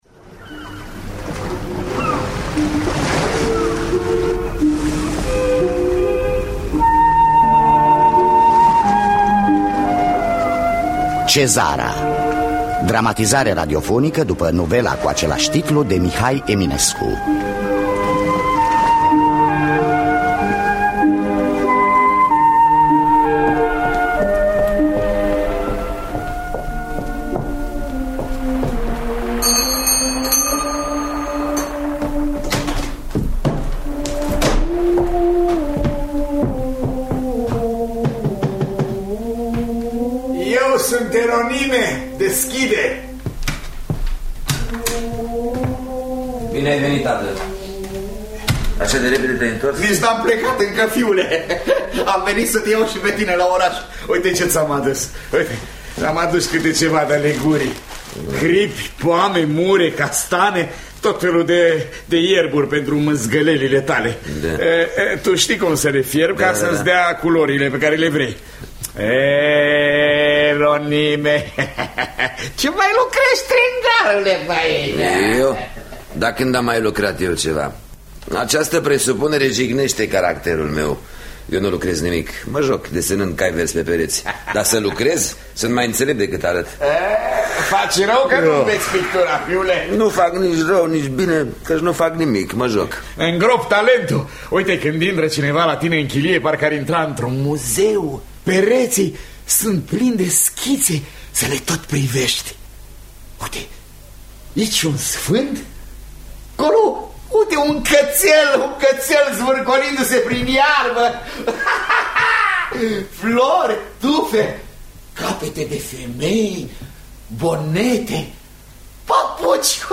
Cezara de Mihai Eminescu – Teatru Radiofonic Online